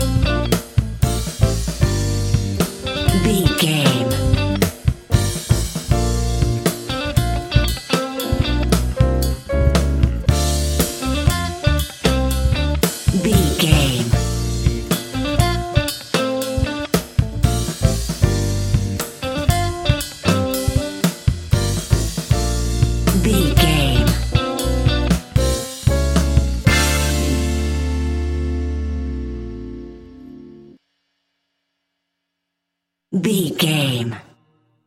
Ionian/Major
A♯
house
electro dance
synths
techno
trance
instrumentals